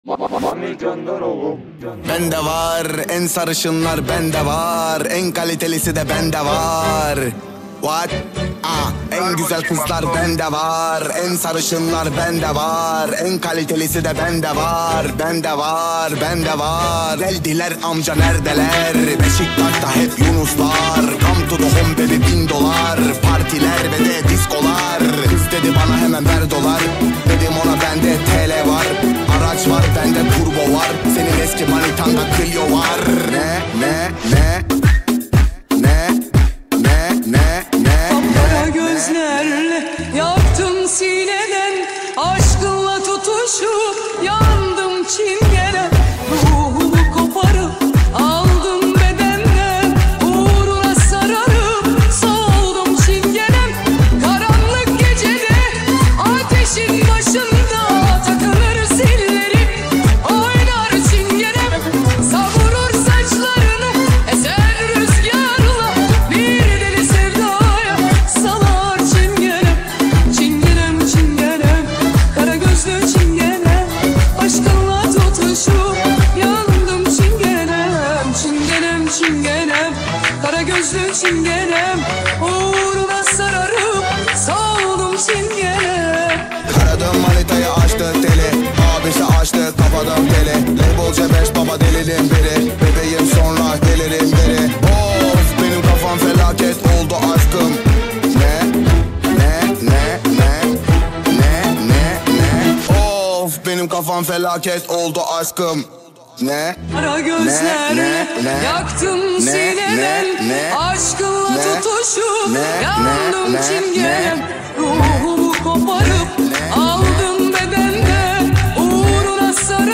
نام اثر: ریمیکس
ژانر: پاپ